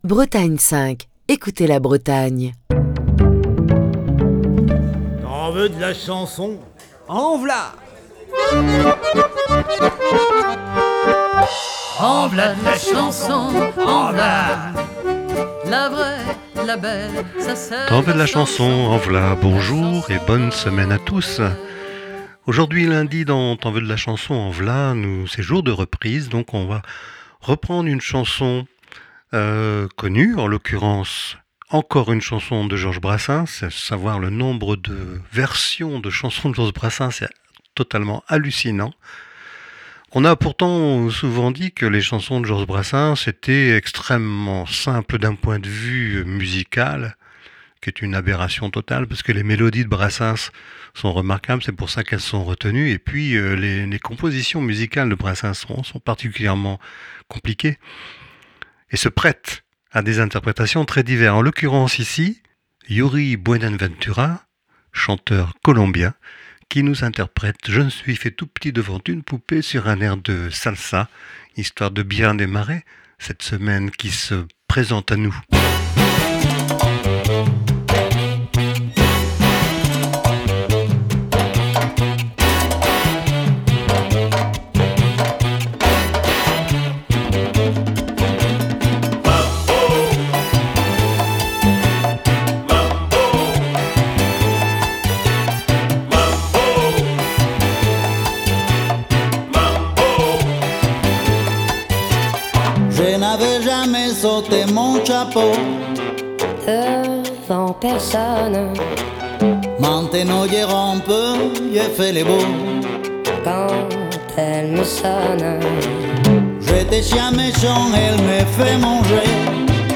C'est sur un air de salsa
le chanteur colombien